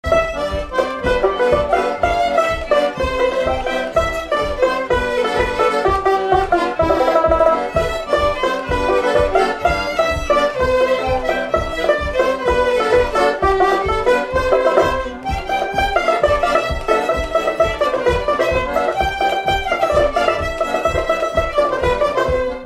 Instrumental
danse : séga
Pièce musicale inédite